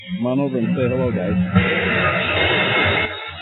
Here at Devils Den, I call for guys to come over and the response was Ten Hut!!!